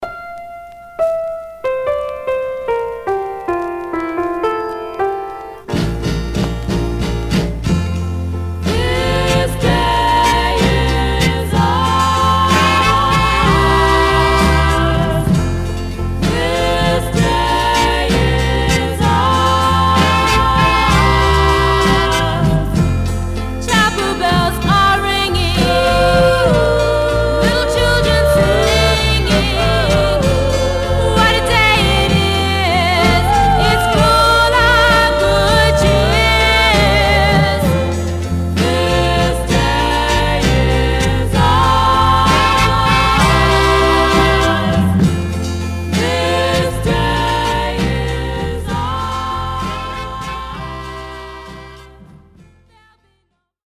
Some surface noise/wear
Mono
Black Female Group